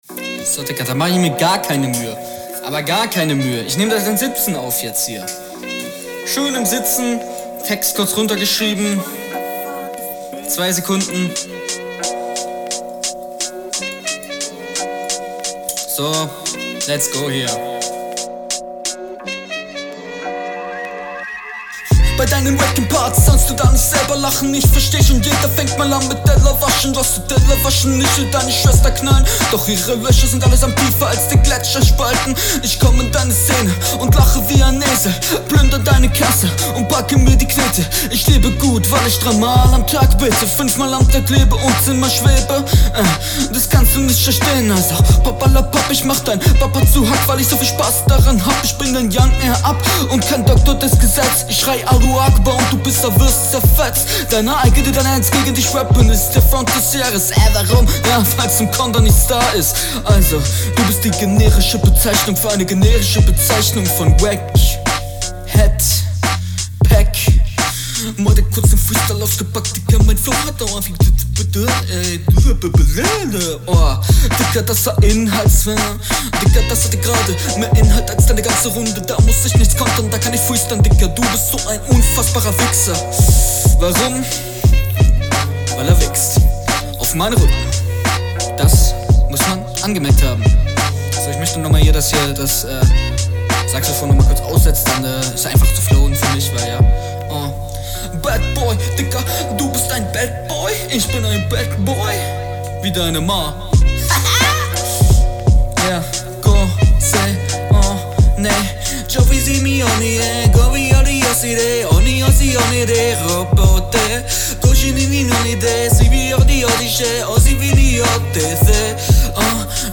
Flow: ➨ Takt triffst du diesmal nicht so gut tbh.
Flow: Du rappst auf dem Takt aber betonst teilweise etwas komisch.